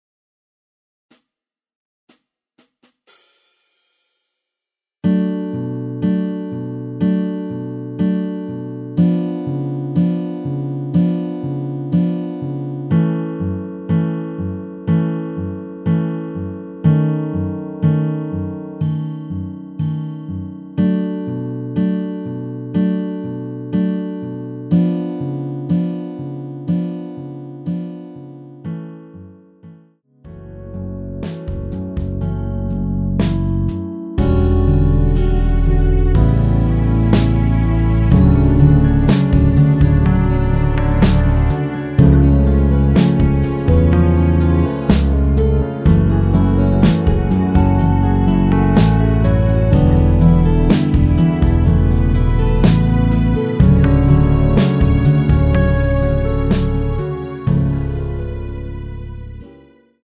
전주 없이 시작 하는 곡이라 카운트 넣었습니다.
대부분의 남성분이 부르실수 있는 키로 제작 하였습니다.
Bm
앞부분30초, 뒷부분30초씩 편집해서 올려 드리고 있습니다.
중간에 음이 끈어지고 다시 나오는 이유는